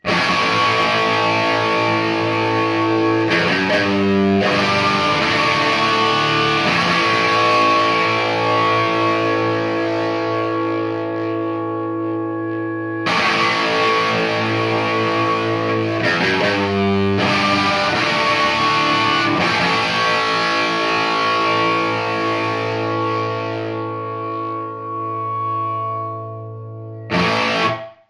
Le tout enregistré avec la même guitare, le même micro positionné au même endroit avec le même baffle (Rivera monté en Scumbacks).
La guitare est une vieille Les Paul Junior de '59, montée en P90 donc.
Marshall Superlead
Les Paul Jr et Marshall Superlead.mp3